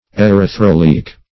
Search Result for " erythroleic" : The Collaborative International Dictionary of English v.0.48: Erythroleic \Er`y*thro"le*ic\, a. [Gr.
erythroleic.mp3